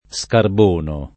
scarbono [ S karb 1 no ]